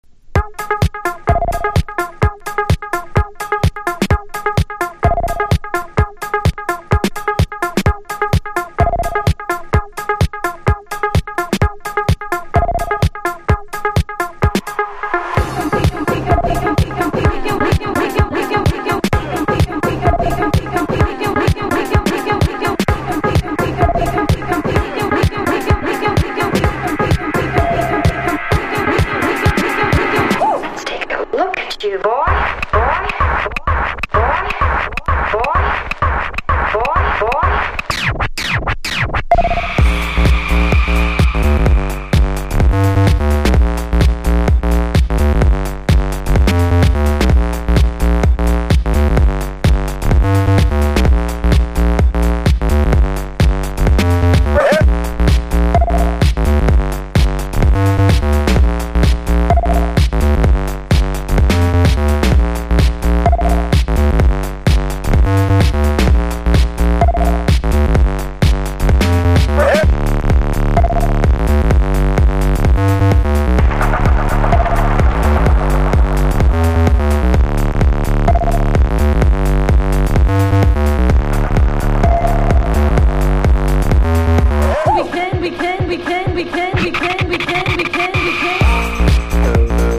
• HOUSE